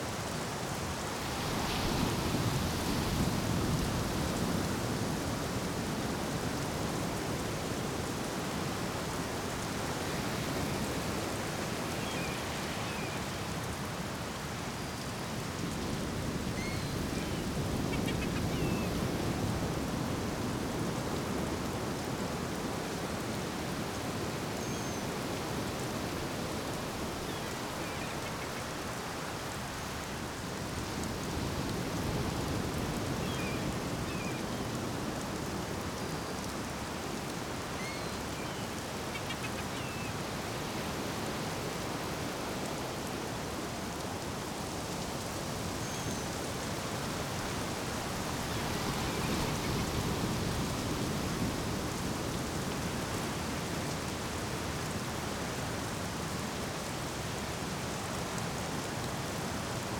Beach Rain.wav